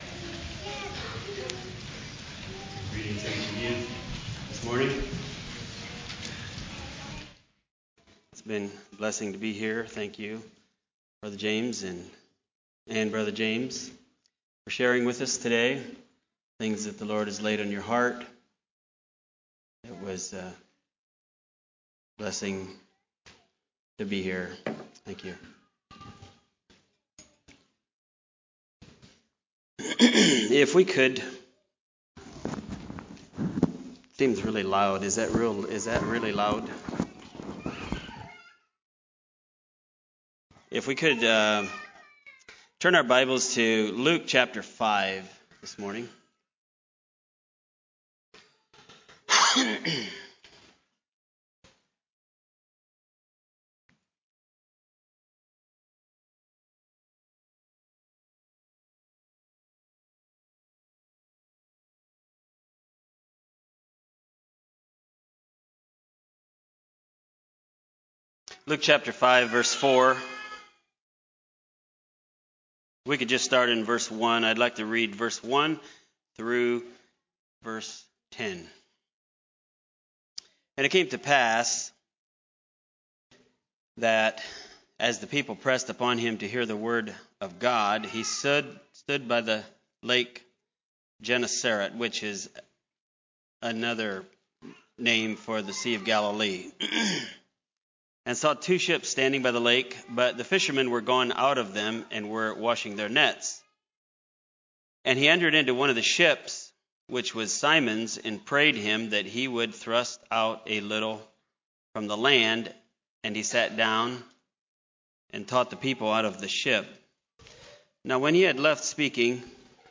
ACCF Sermons